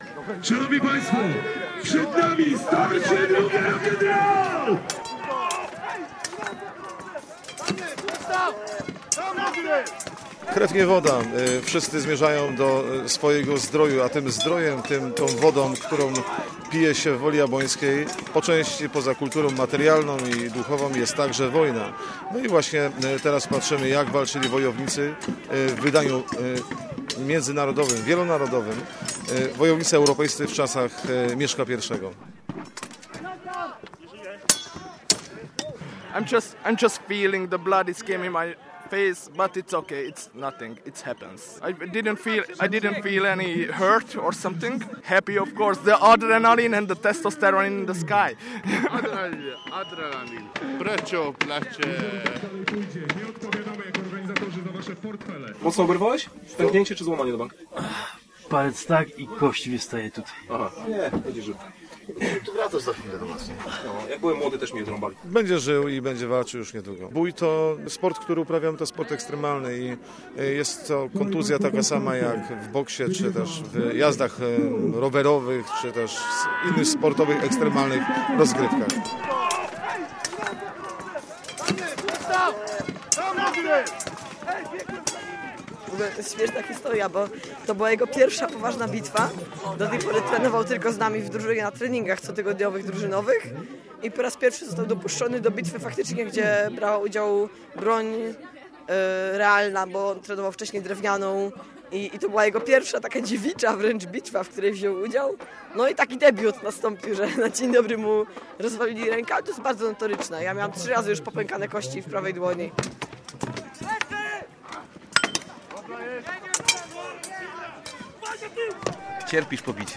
Bój - reportaż
Krew, łzy i szczęk broni. W miniony weekend w Uroczysku Wołkołatka pod Nowym Tomyślem rozpoczął się sezon bitew średniowiecznych wojów. Przyjechali zbrojni z zagranicy, a w polu bitwy obok mężczyzn dzielnie walczyły też panie.